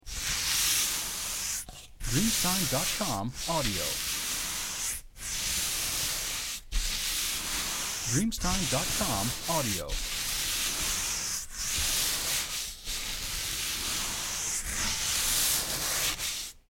Rallentamento 2
• SFX